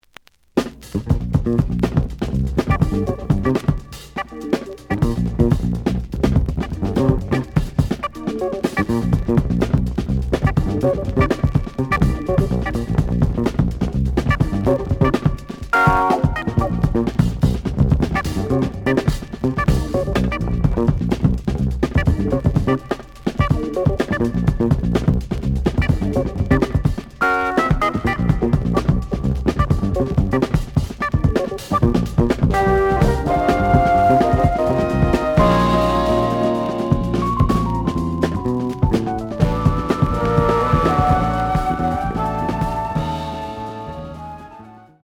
The audio sample is recorded from the actual item.
●Genre: Jazz Funk / Soul Jazz